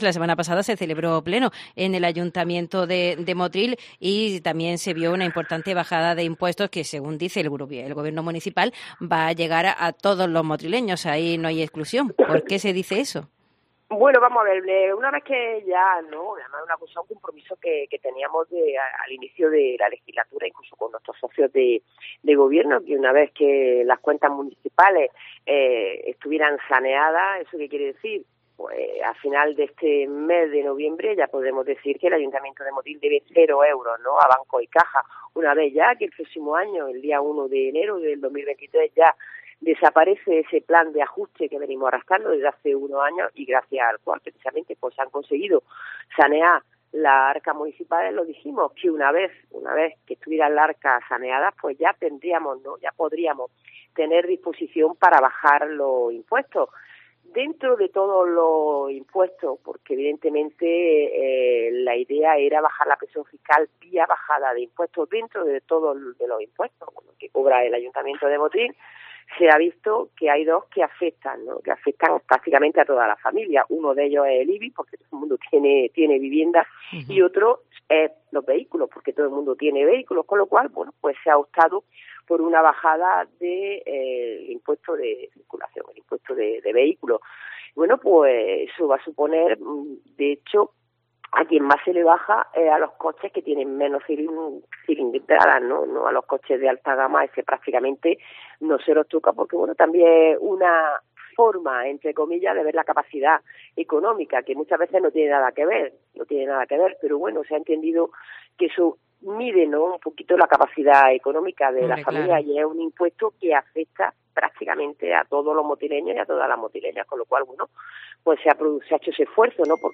También hablamos con la alcaldesa de la bajada de impuestos, de la rotonda de Ron Montero, bailes para mayores y actividades en Navidad